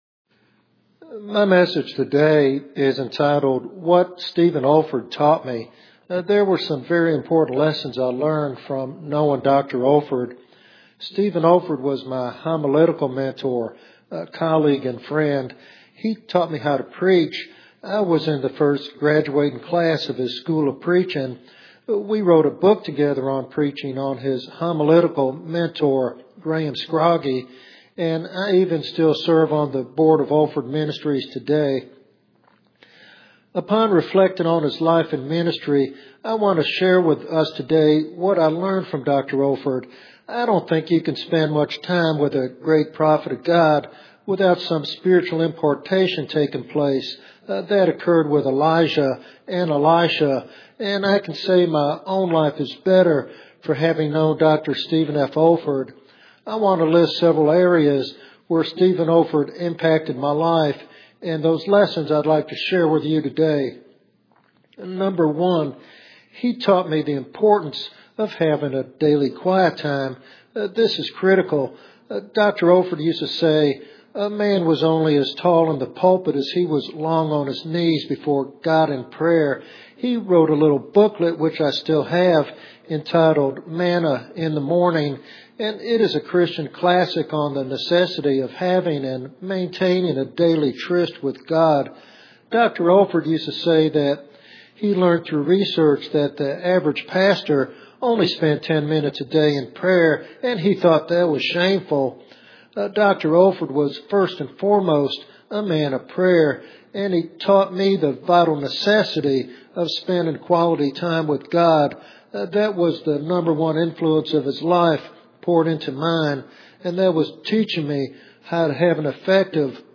In this biographical sermon